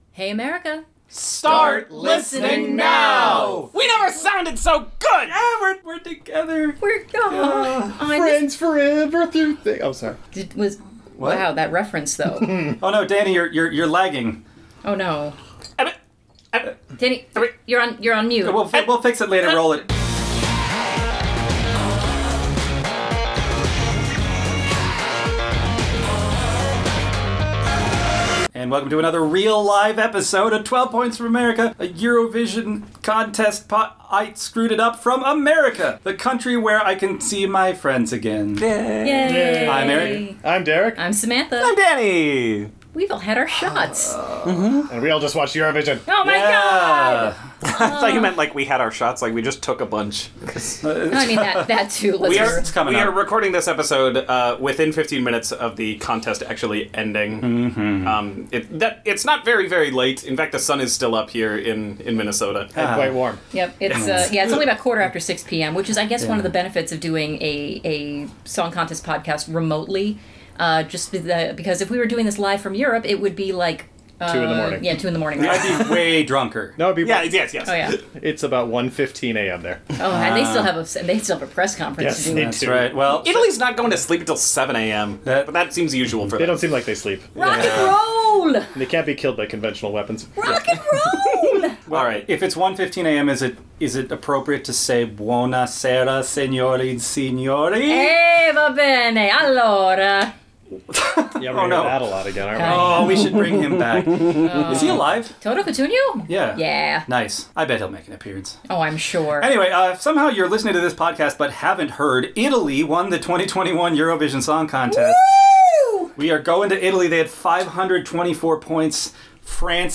After a phenomenal competition, Italy have prevailed as winners of the 2021 Eurovision Song Contest! Recorded moments after the end of the contest, we give our immediate reactions to the night’s festivities, including the winner, other surprising scores, and what the results mean for the future of the contest.